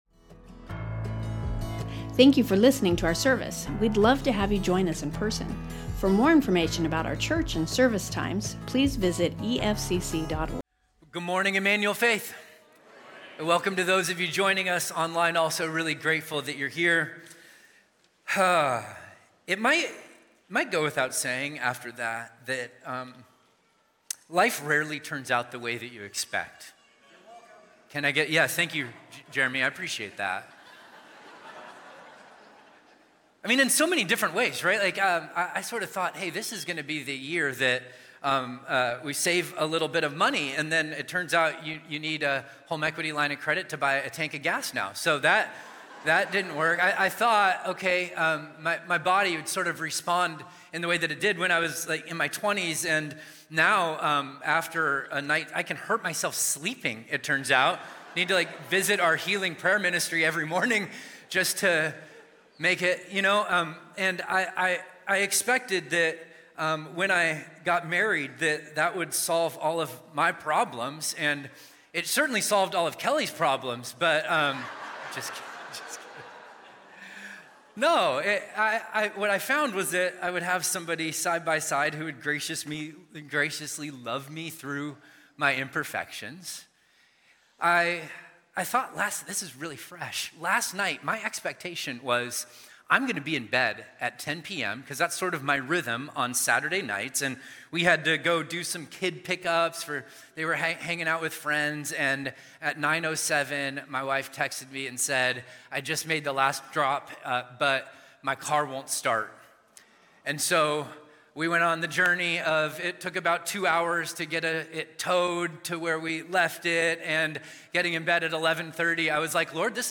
Emmanuel Faith Sermon Podcast The Way of the Cross | 1 Peter 2:21-25 Mar 30 2026 | 00:42:16 1x 00:00 / 00:42:16 Subscribe Share Spotify Amazon Music RSS Feed Share Link Embed